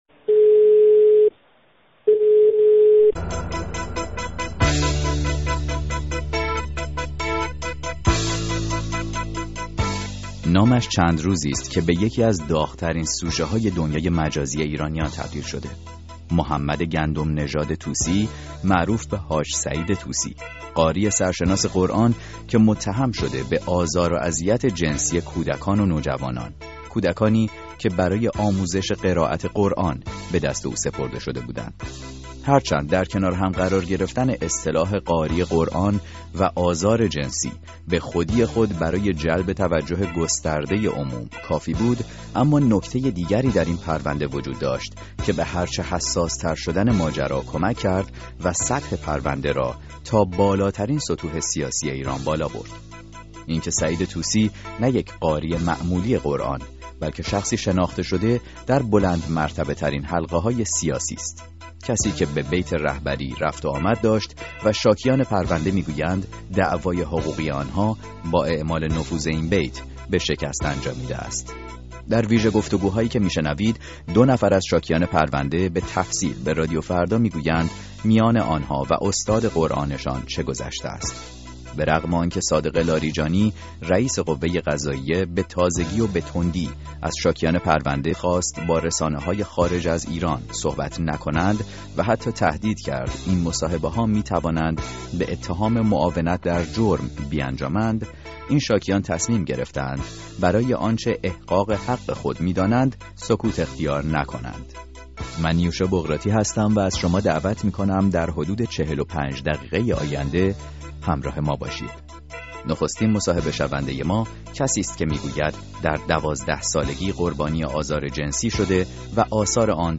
ساعت ششم - مصاحبه